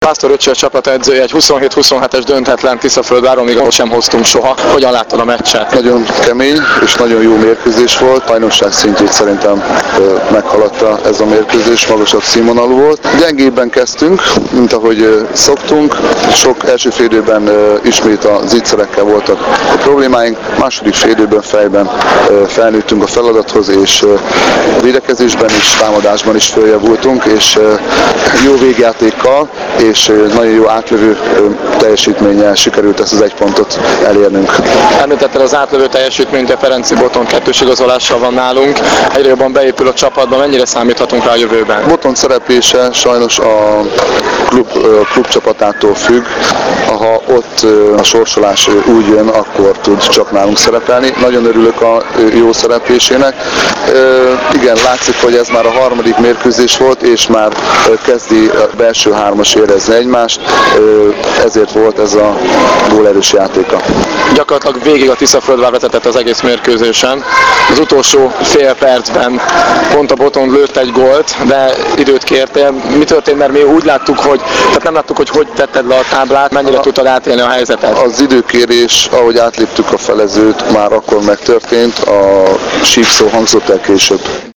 Az interjú mp3-ban >>>